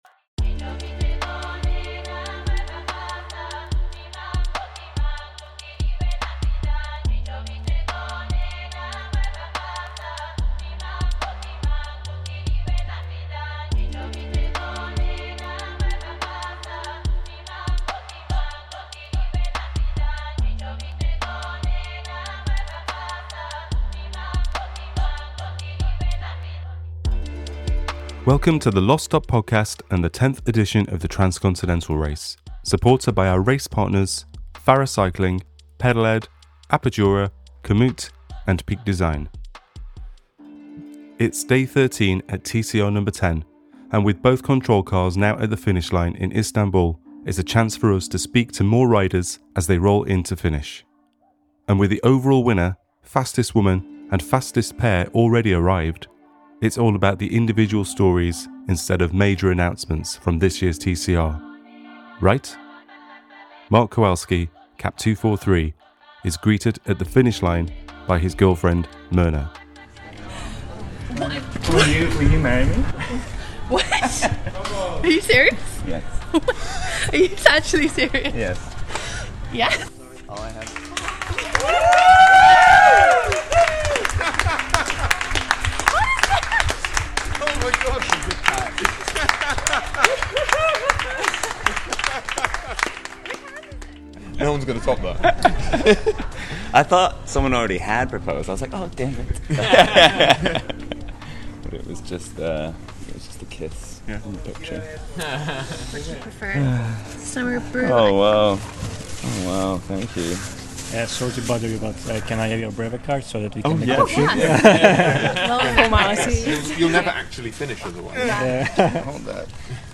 TCRNo10 | Day 13 Aug 04, 2024, 05:58 PM Headliner Embed Embed code See more options Share Facebook X Subscribe TCRNo10 // Day 13 With the full TCR Media Team in Istanbul, it’s a chance to capture even more stories from riders as they conclude their TCRNo10 journeys. Joy and relief are abundant, especially with a surprise proposal at the finish line!